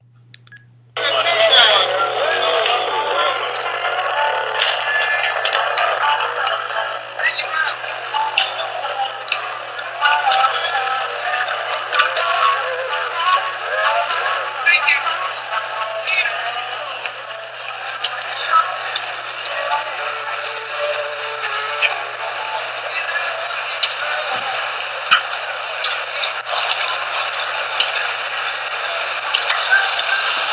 Field Recording 12
Location: A street Corner in New Orleans
Sounds: Harmonica, Guitar, Bass, People Talking, Taxis, Change rattling
Nawlins-Band1.mp3